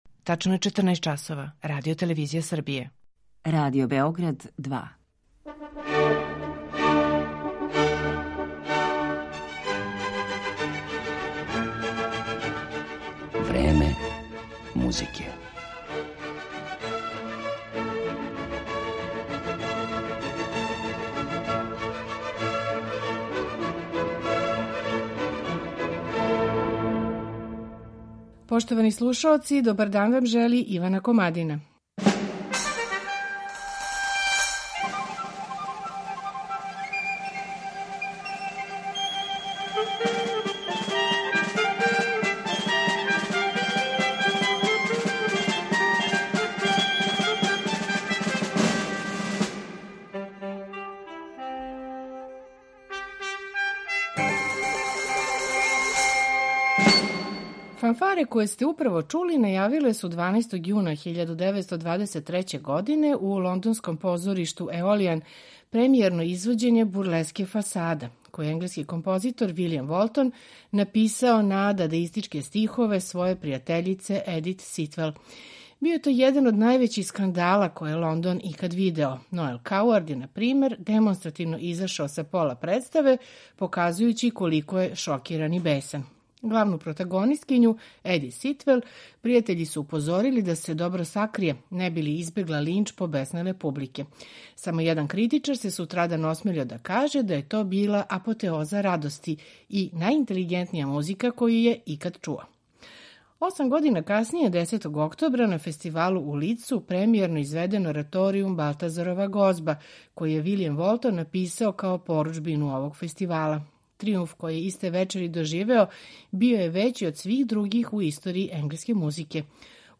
У данашњем Времену музике покушаћемо да одговоримо шта је то што је Волтона непрестано водило од катастрофе до тријумфа. Слушаћете његове концерте за виолину и виолу, бурлеску „Фасада", ораторијум „Балтазарова гозба", „Крунидбени марш".